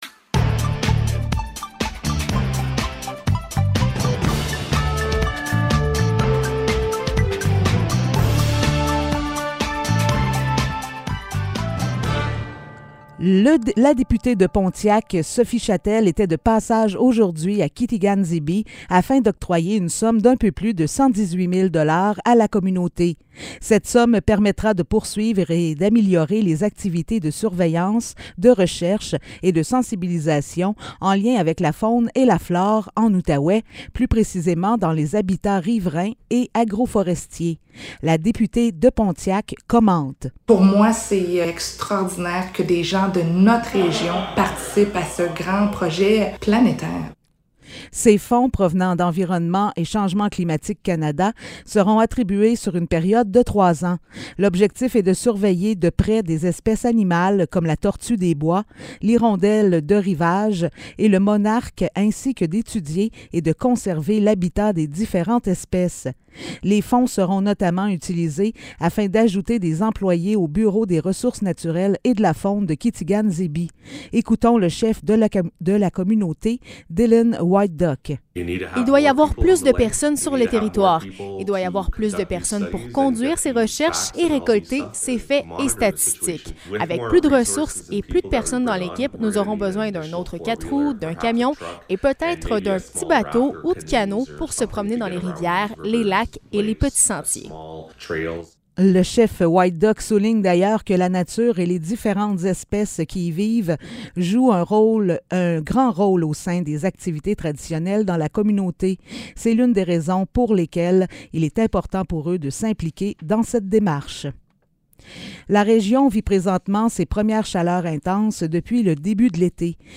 Nouvelles locales - 20 juillet 2022 - 15 h